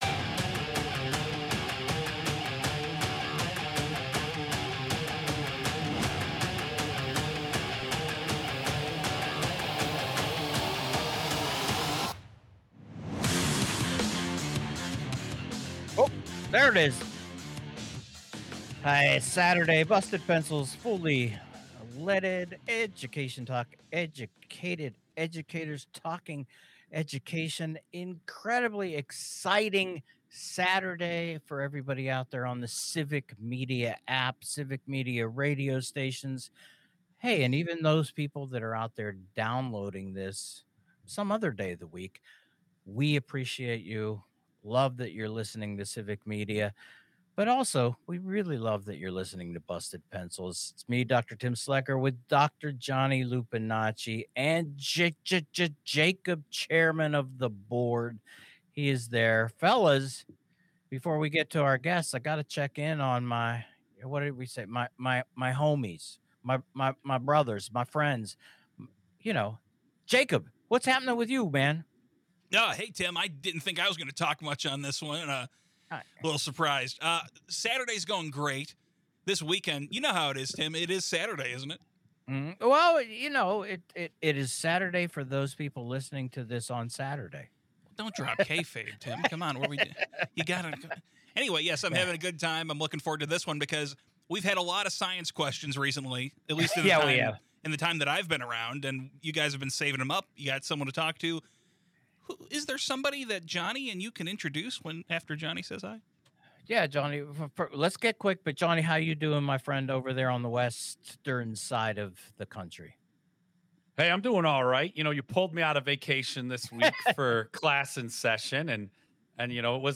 Science expert extraordinaire Ethan Siegel stops by the show to help clear up our burning science-based questions.